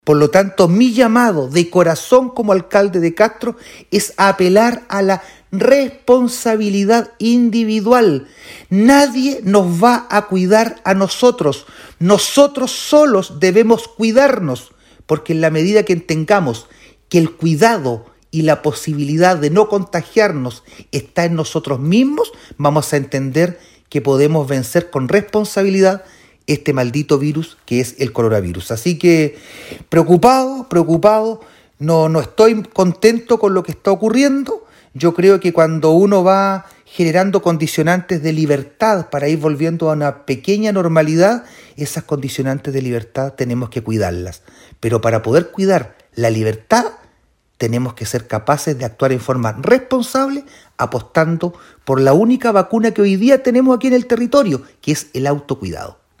Un particular llamado a reforzar las medidas de autocuidado este año 2021 realizó el alcalde de Castro Juan Eduardo Vera,
En tal sentido llamó a la comunidad chilota, y castreña en particular, en tono de súplica a apelar a la responsabilidad personal y colectiva en este año que comenzamos tomando en cuenta que el virus no ha desaparecido y que sigue muy presente entre nosotros.